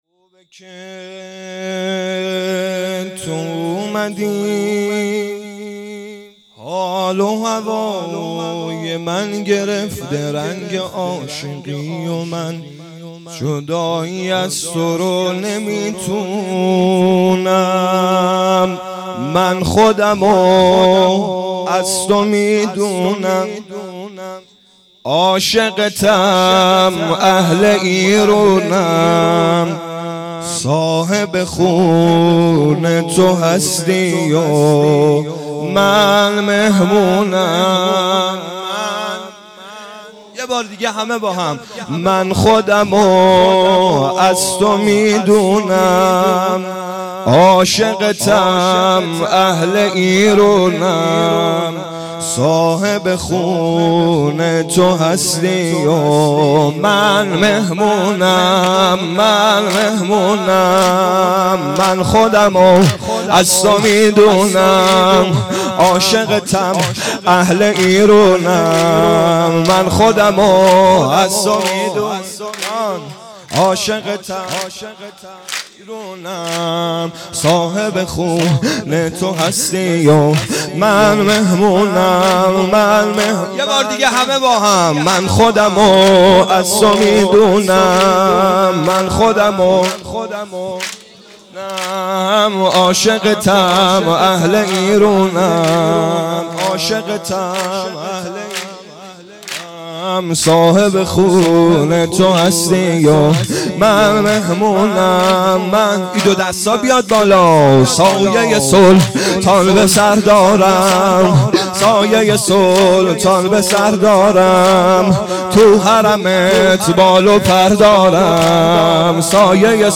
خیمه گاه - بیرق معظم محبین حضرت صاحب الزمان(عج) - سرود ا من خودمو از تو میدونم